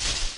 footstep_tall_grass.ogg